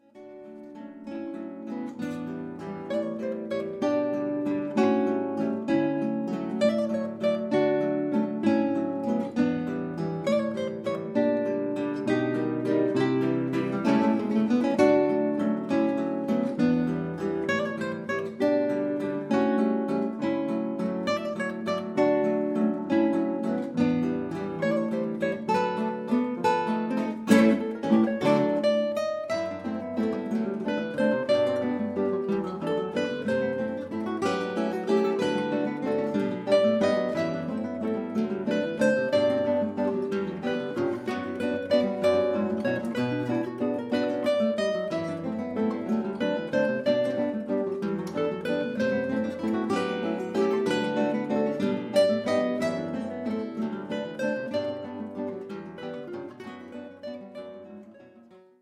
Die Hörproben des Quartetts sind ungeschnitten und nicht nachbearbeitet, daher ist nicht mit Aufnahmen in Studio-Qualität zu rechnen.
Die Stücke sind aus verschiedenen Epochen und Stilen ausgewählt, um die Vielseitigkeit des fantastischen Instruments Konzertgitarre zu zeigen.